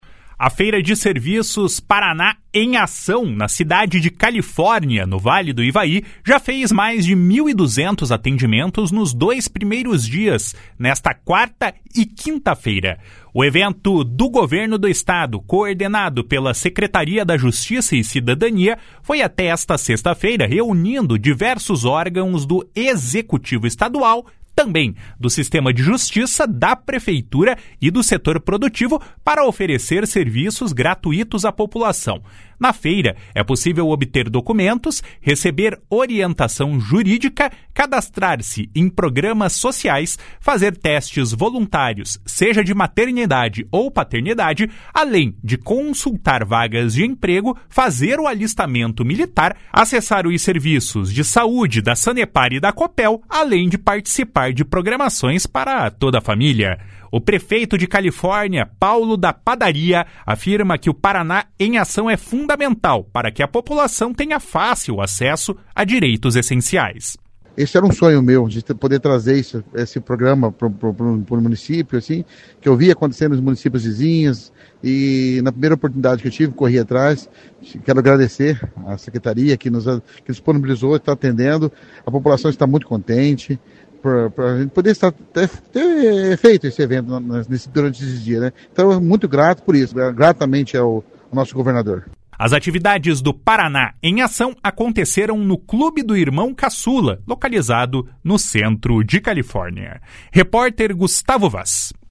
O prefeito Paulo da Padaria afirma que o Paraná em Ação é fundamental para que a população tenha fácil acesso a direitos essenciais. // SONORA PAULO DA PADARIA //